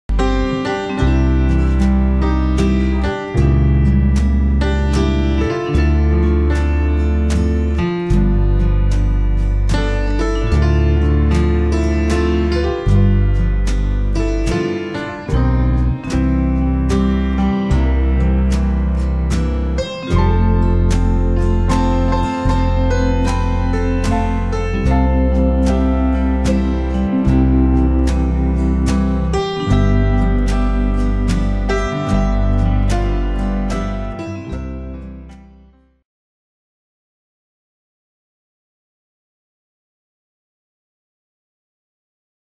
20 CLASSIC PIANO INSTRUMENTALS